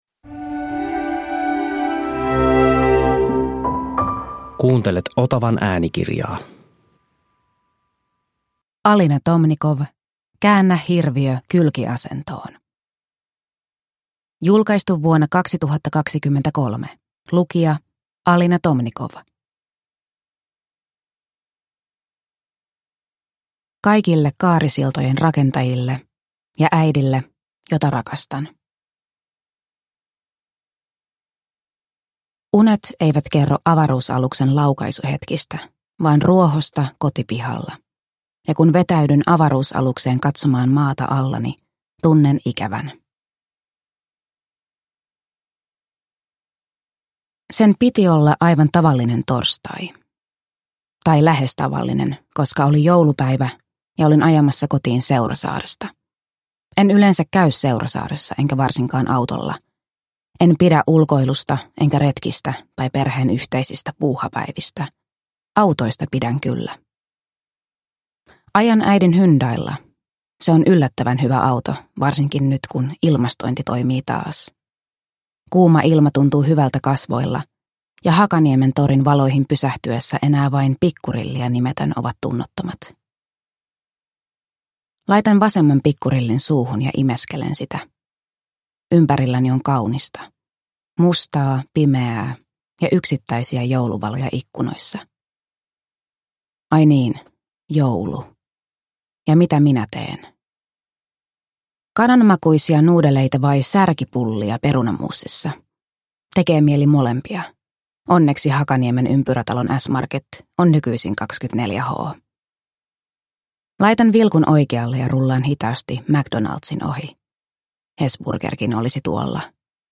Käännä hirviö kylkiasentoon – Ljudbok – Laddas ner